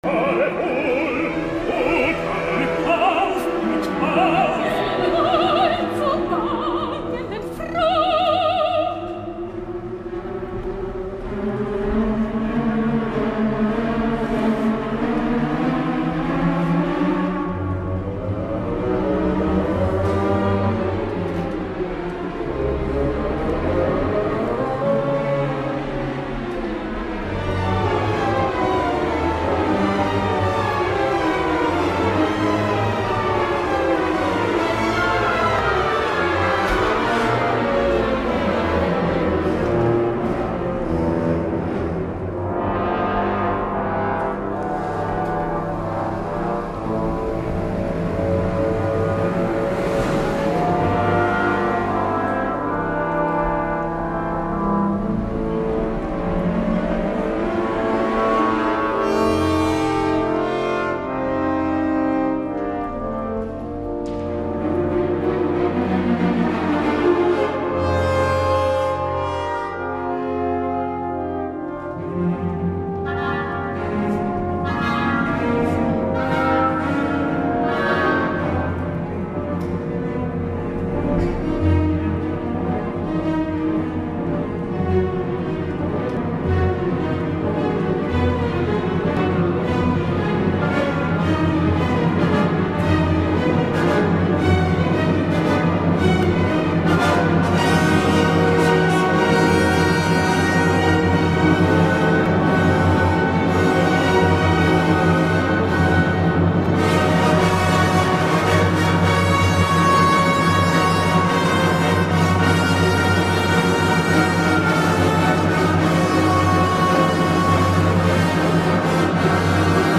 El més notable del pròleg i primera jornada ho he trobat en la direcció de Kirill Petrenko, que ja ens havia deixat un esperançador Rheingold amb l’Accademia santa Cecilia.
El director rus és molt clar en la seva exposició vigorosa i també contundent.